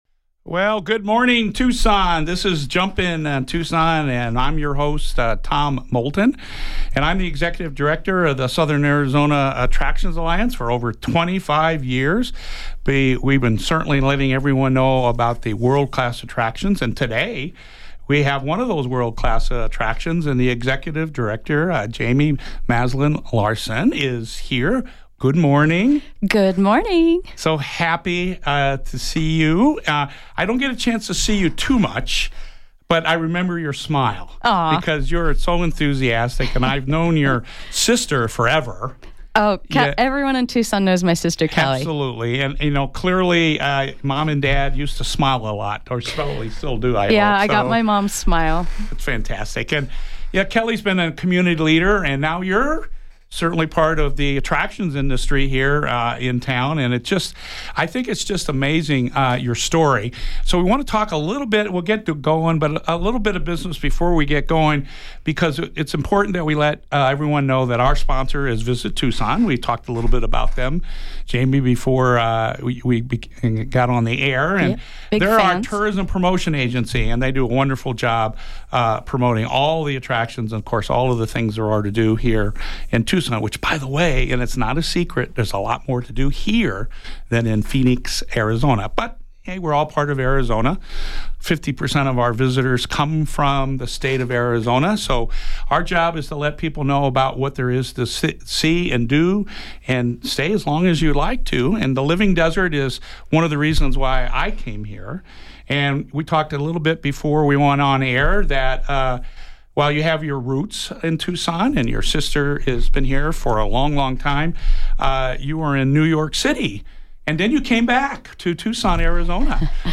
Based on the 7/25/24 Jump In Tucson episode on KVOI-1030AM in Tucson, AZ.